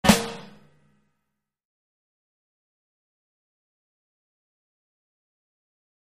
Snare Flam Orchestra With Room - Attention